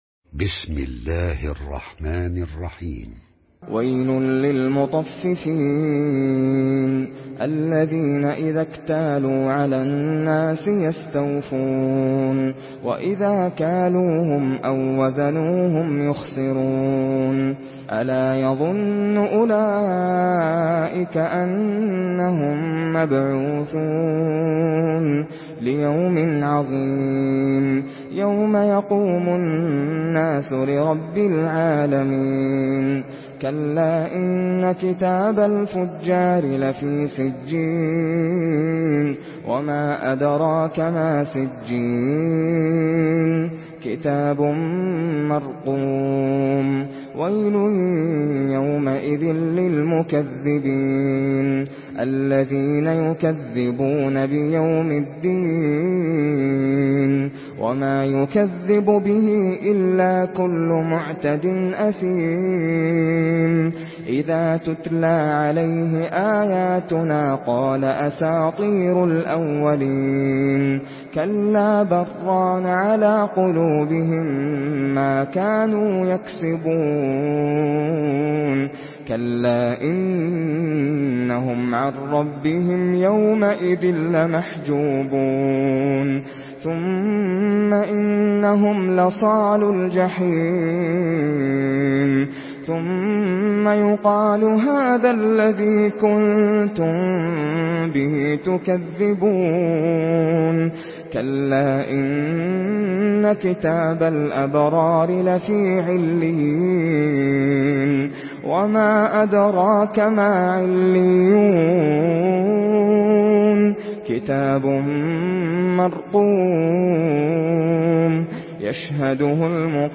Surah আল-মুতাফফিফীন MP3 by Nasser Al Qatami in Hafs An Asim narration.
Murattal